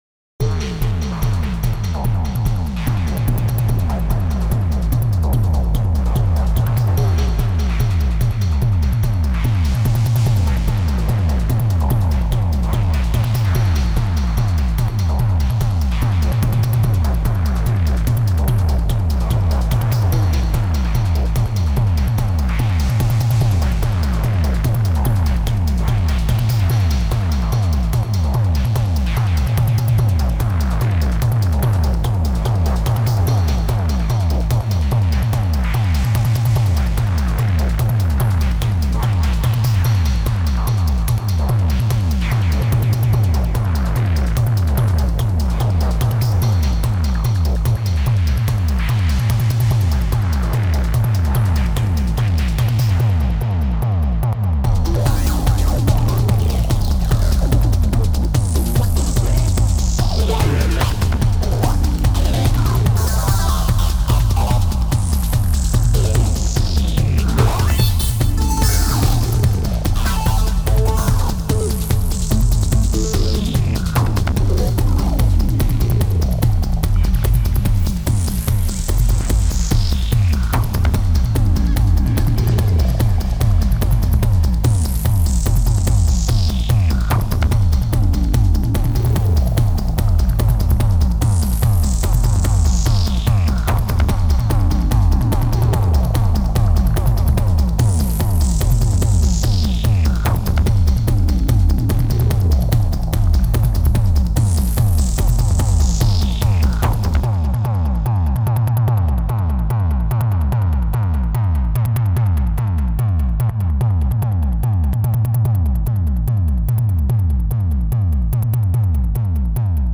psy trance
ich hab mich immoment für 4 spuren analog belegt mit Robota2(jeweils 4 slots) und beatbox2(2-4slots .......12 sind noch frei) und 3 synths . mach summasumarum 7Spuren .
Leider stimmt das Timming der Midimap überhaupt nicht zum abspielmarker - irgendwas ist da faul , weswegen so ziemlich alles nicht wirklich getimmt sein kann .
die synths sind probe , um zu schauen , was sie können ....und passen könnten .